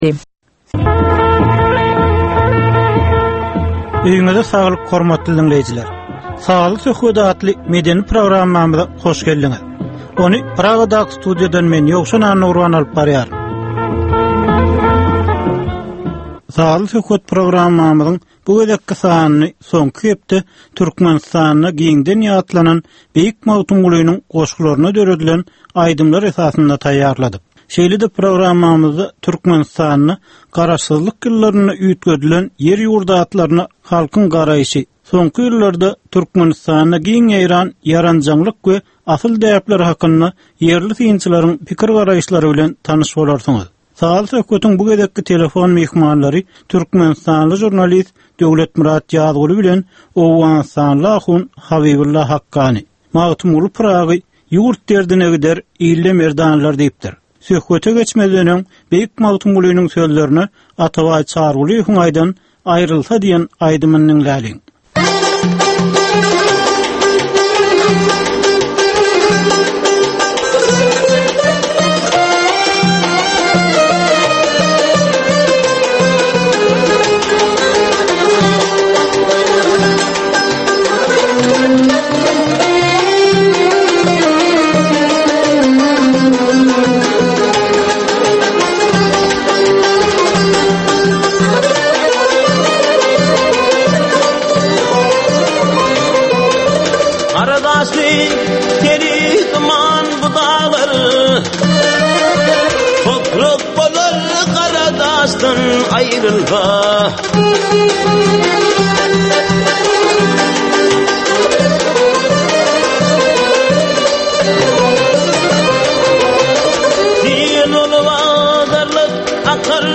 Türkmenistanyň käbir aktual meseleleri barada sazly-informasion programma.